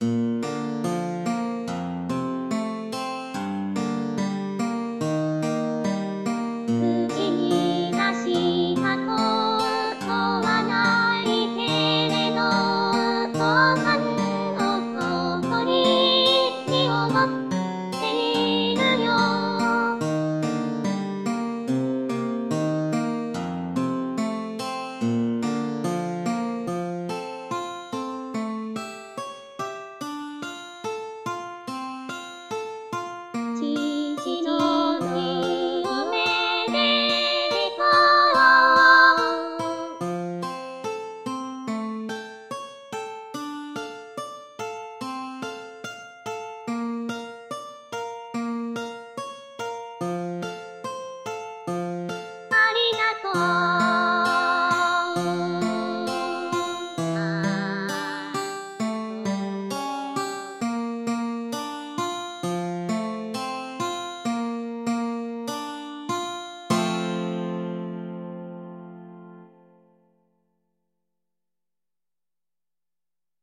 andante